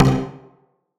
Synth Stab 10 (C).wav